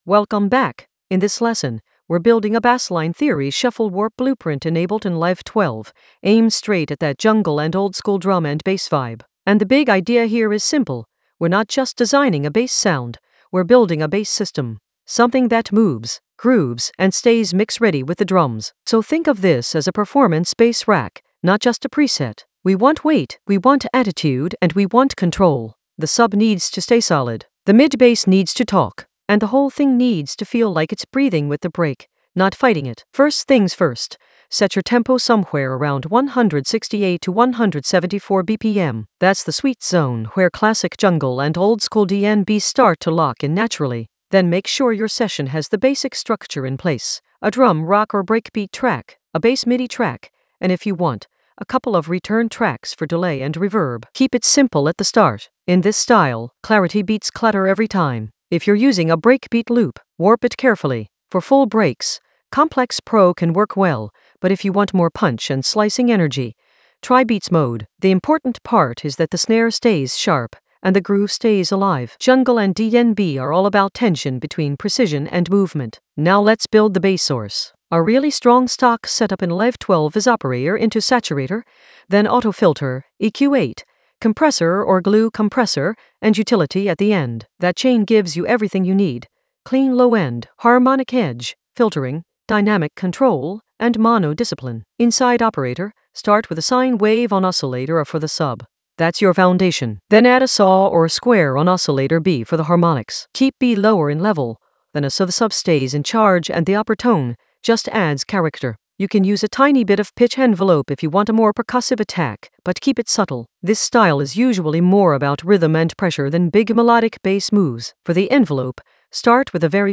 Narrated lesson audio
The voice track includes the tutorial plus extra teacher commentary.
An AI-generated intermediate Ableton lesson focused on Bassline Theory shuffle warp blueprint using macro controls creatively in Ableton Live 12 for jungle oldskool DnB vibes in the Mixing area of drum and bass production.